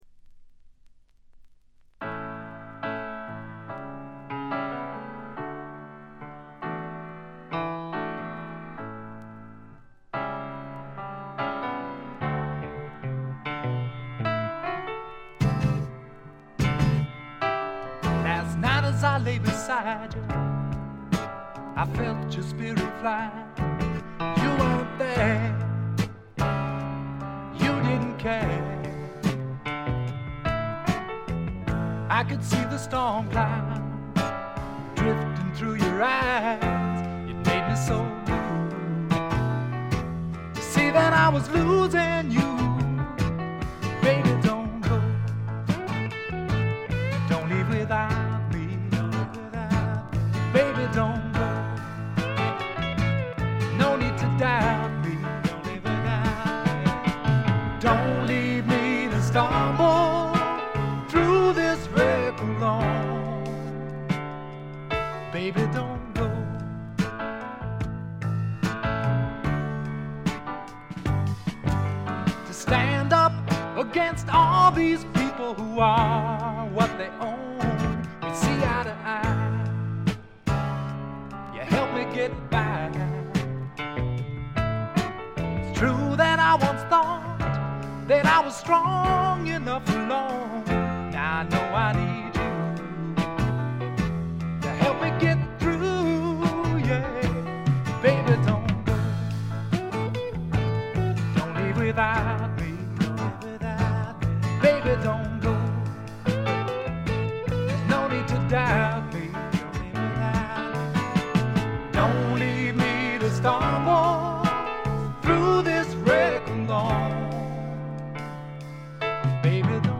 軽微なチリプチ。散発的なプツ音少し。
シンプルなバックに支えられて、おだやかなヴォーカルと佳曲が並ぶ理想的なアルバム。
試聴曲は現品からの取り込み音源です。